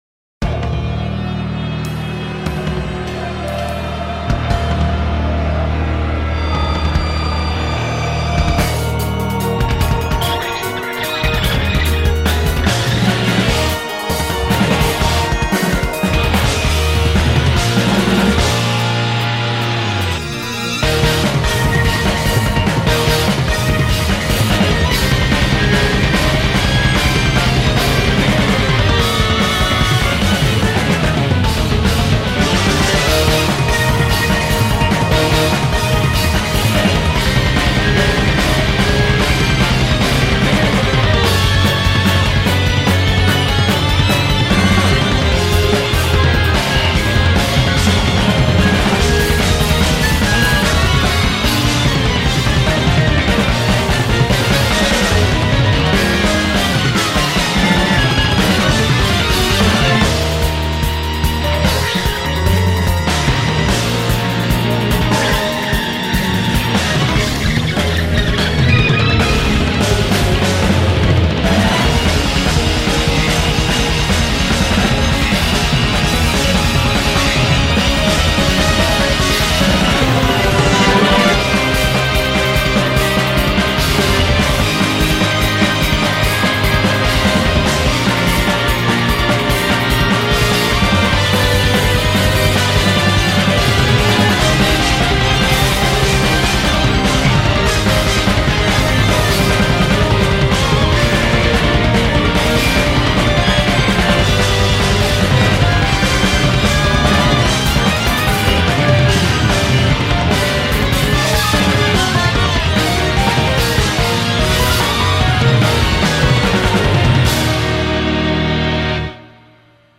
BPM147
Audio QualityPerfect (Low Quality)